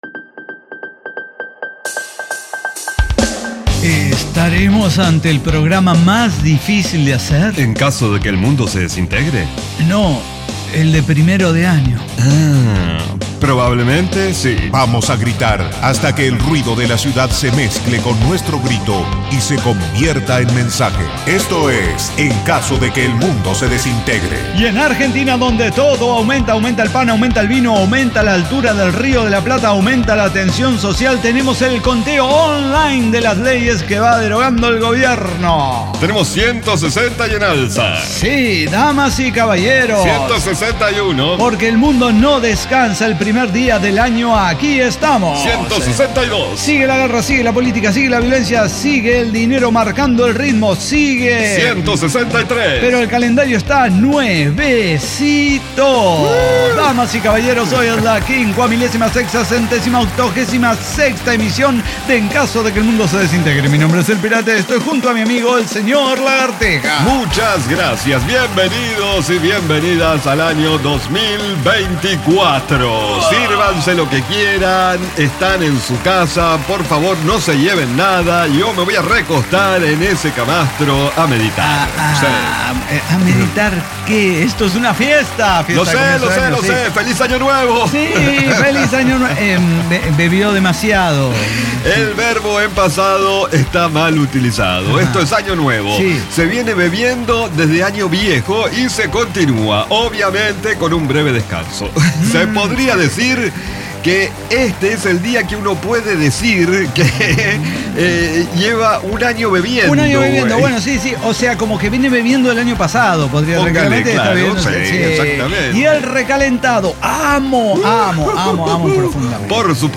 ECDQEMSD podcast El Cyber Talk Show